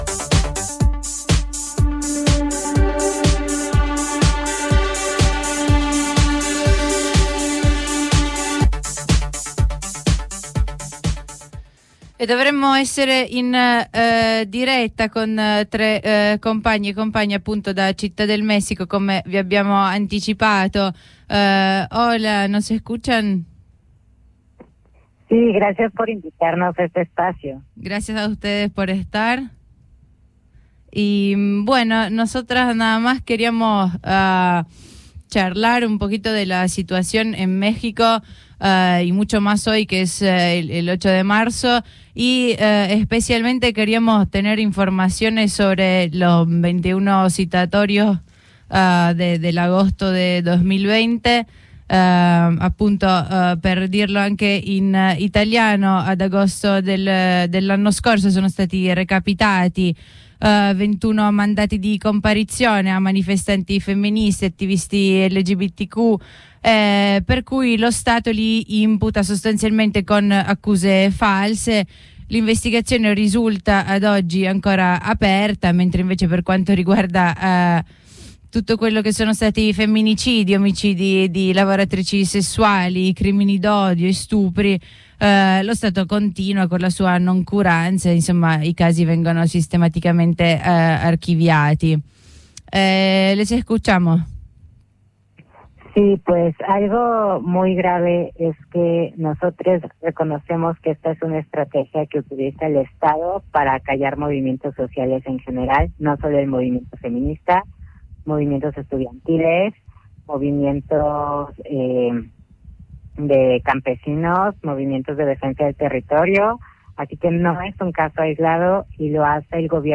Ascolta la diretta con tre compagnx di Resistencia Queer, collettivo anarchico transfemminista di persone LQBTTTIQA+ e artistx dissidentx di Città del Messico.